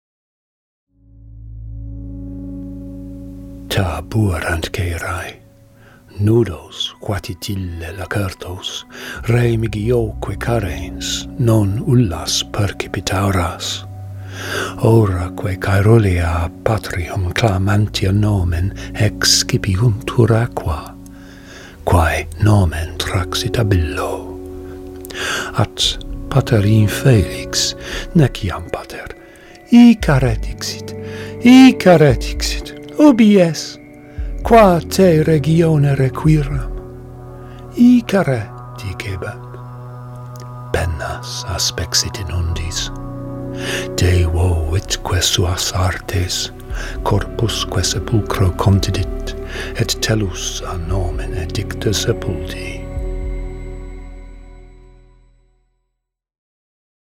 Music by Bob Bradley and Thomas Balmforth.